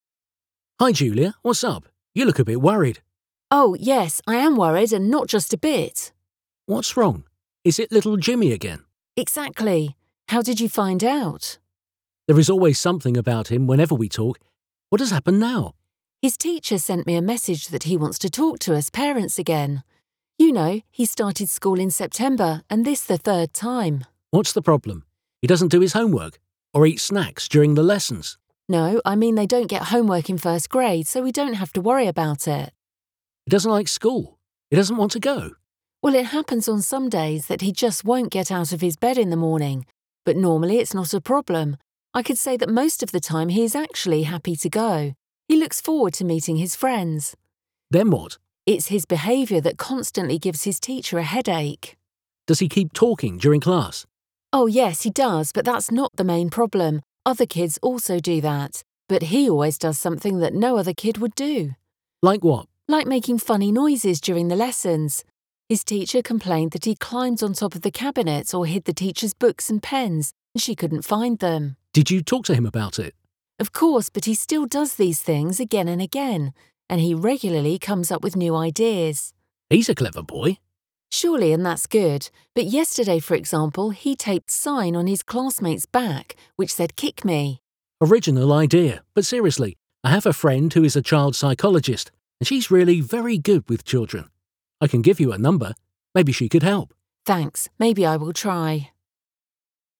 5-Dialogue-Nautghy-Boy.wav